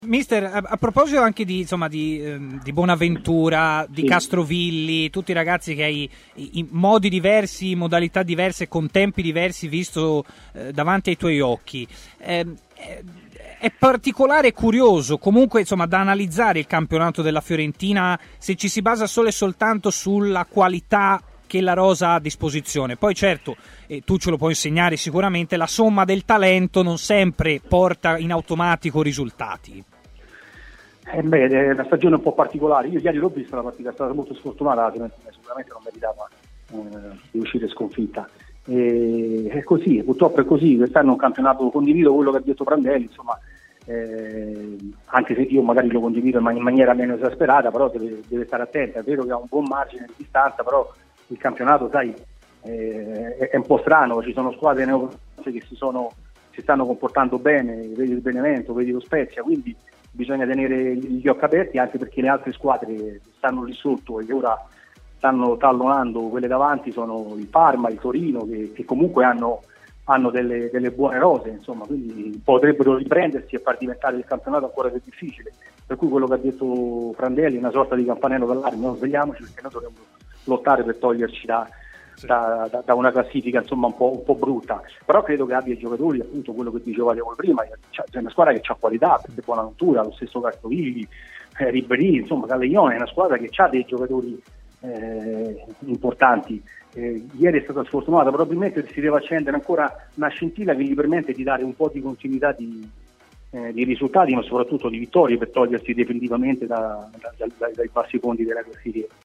L'allenatore Stefano Colantuono ha parlato anche della Fiorentina, durante l'intervista a Stadio Aperto, programma di approfondimento di TMW Radio.